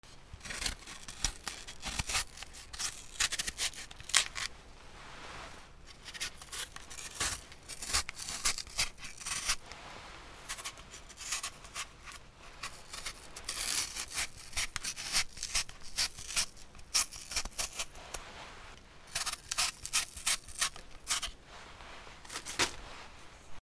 リンゴの皮をむく キッチン 料理
『シャリシャリ』